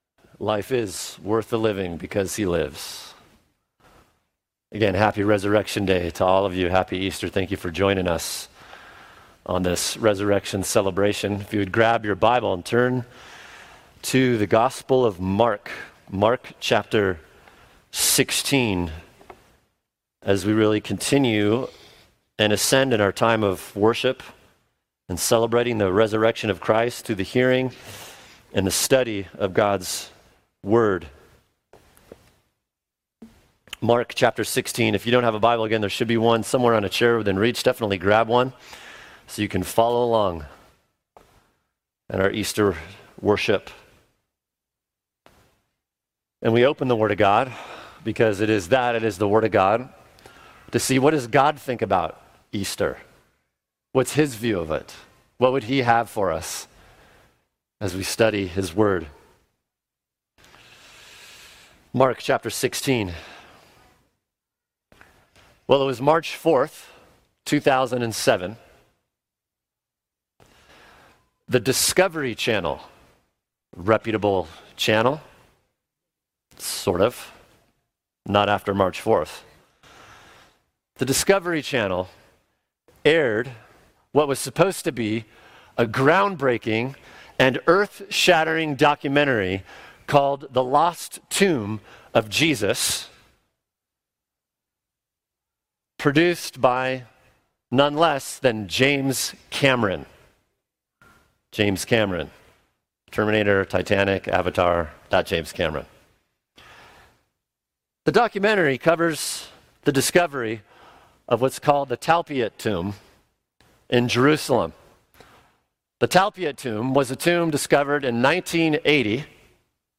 [sermon] Christ is Risen Mark 16:1-8 | Cornerstone Church - Jackson Hole
Resurrection Sunday